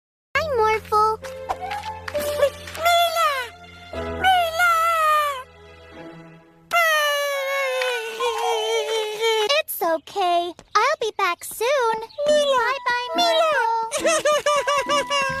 Mila, Milaaaa!! (Cries) -Morphle |